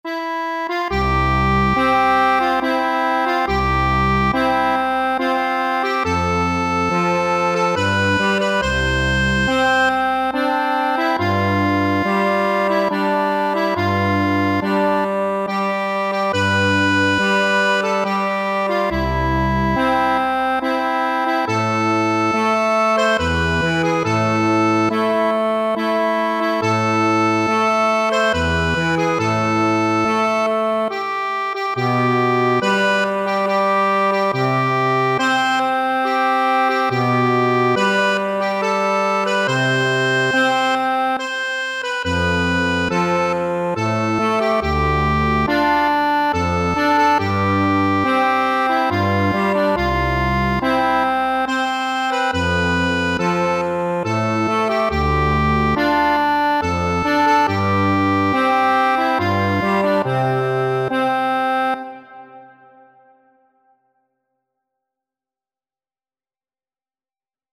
3/4 (View more 3/4 Music)
C5-C6
Maestoso
Accordion  (View more Easy Accordion Music)